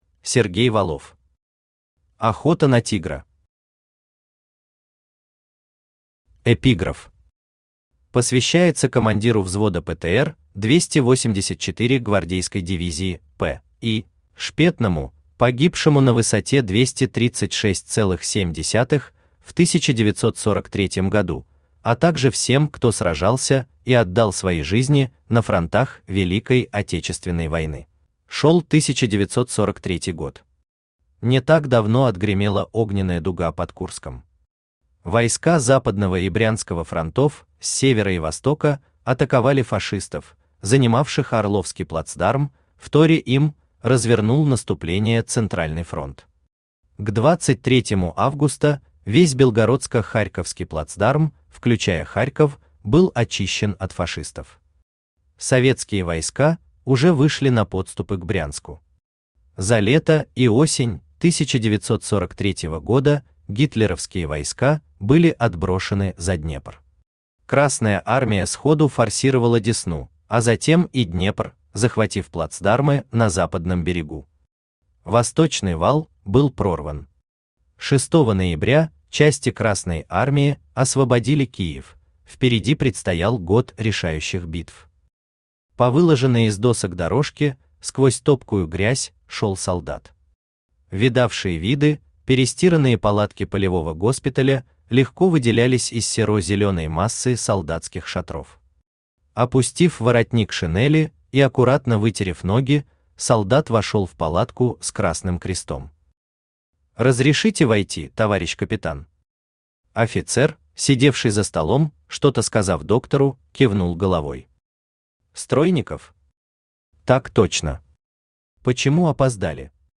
Аудиокнига Охота на Тигра | Библиотека аудиокниг
Aудиокнига Охота на Тигра Автор Сергей Викторович Валов Читает аудиокнигу Авточтец ЛитРес.